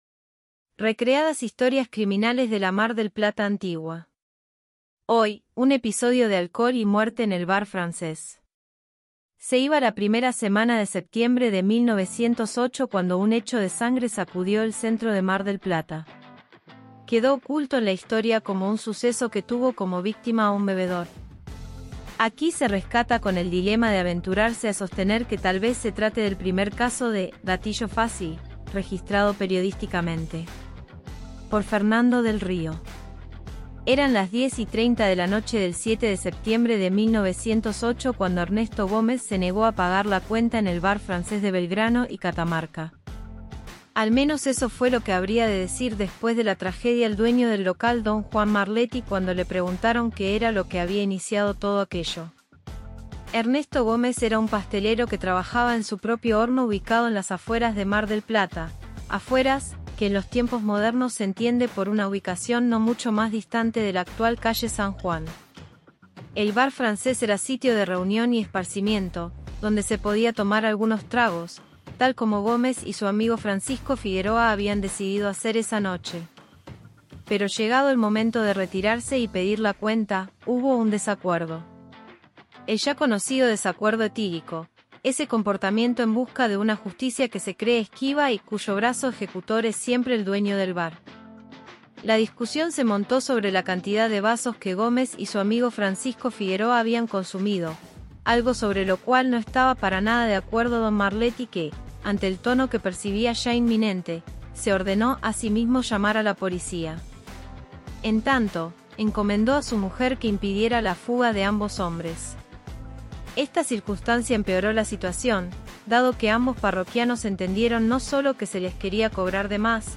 Versión leída por Elena, avatar Inteligencia Artificial.